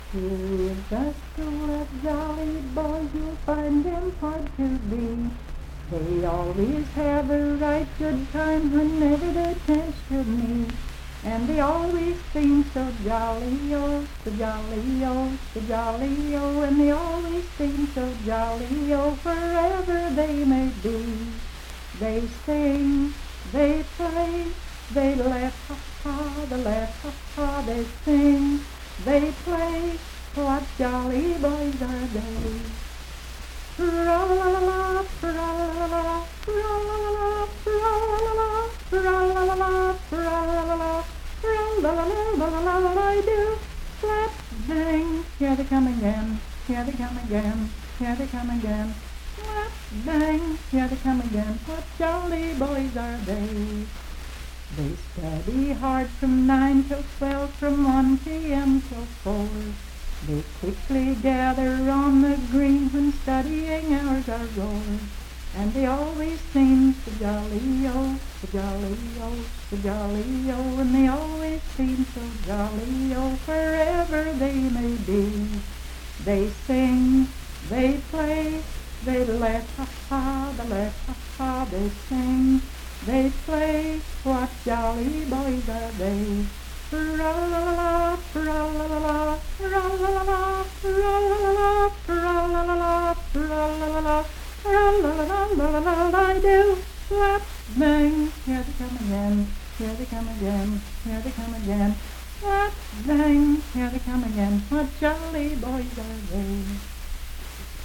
Unaccompanied vocal music
Dance, Game, and Party Songs
Voice (sung)